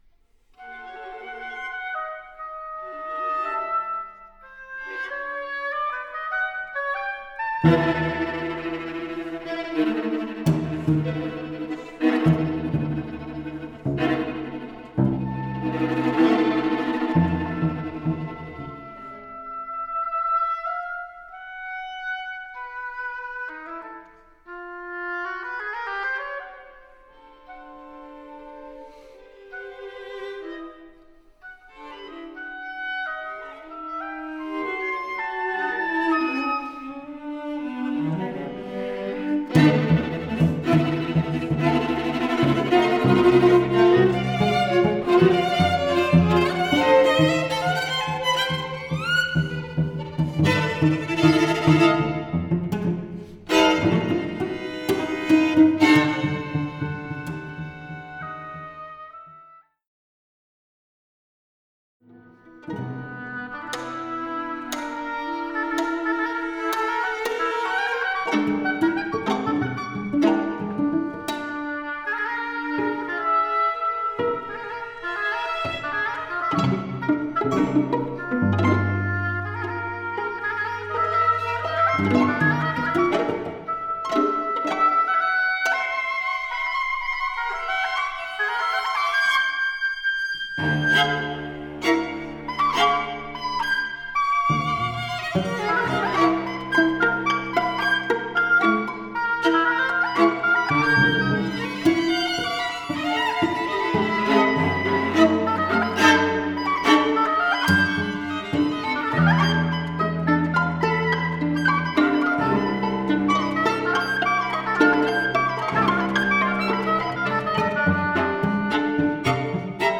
exquisitely lyrical.